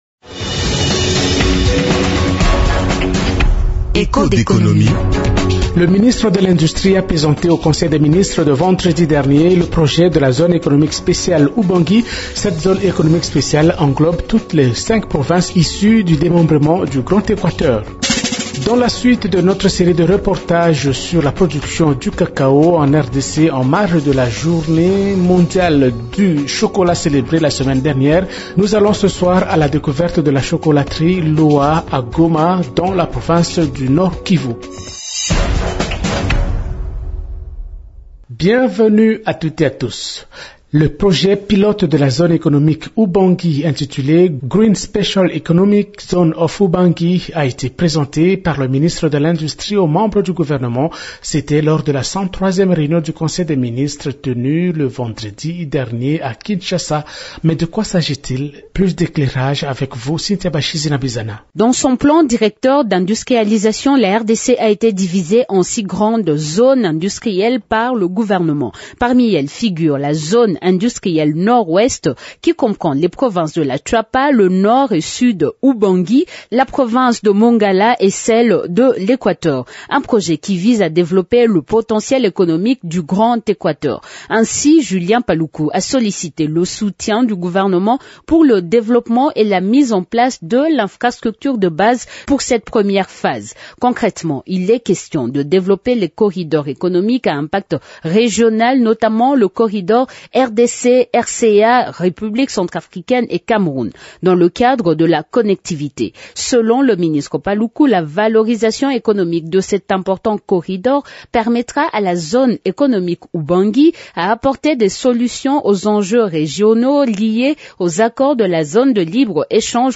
Ainsi, la suite de notre série des reportages sur la production du cacao en RDC, nous sommes allés à la découverte de la chocolaterie Lowa à Goma dans le Nord Kivu.